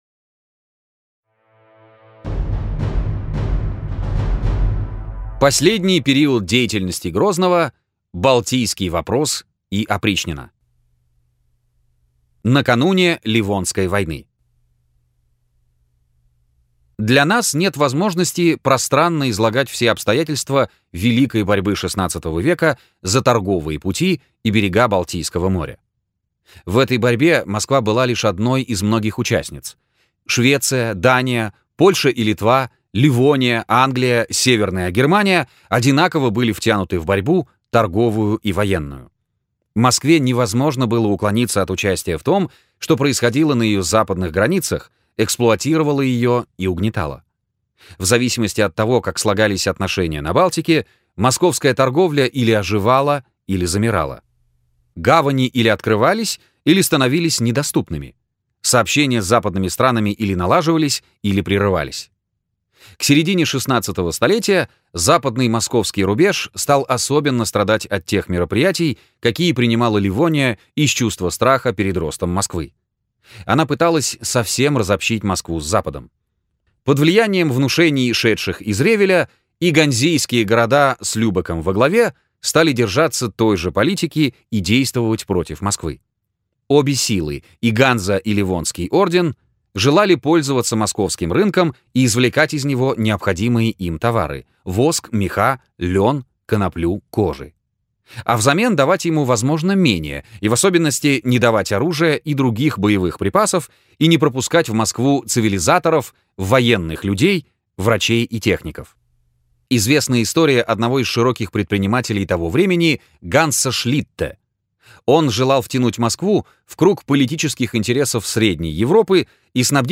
Аудиокнига Иван Грозный | Библиотека аудиокниг